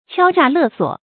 敲榨勒索 注音： ㄑㄧㄠ ㄓㄚˋ ㄌㄜˋ ㄙㄨㄛˇ 讀音讀法： 意思解釋： 見「敲詐勒索」。